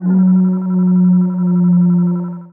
Theremin_Atmos_01.wav